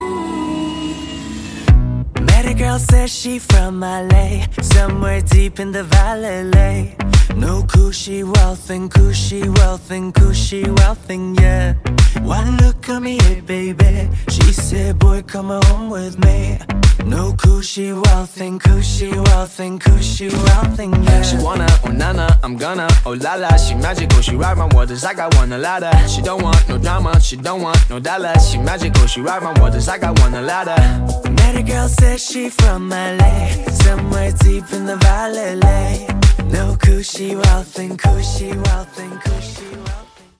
Tonos de canciones del POP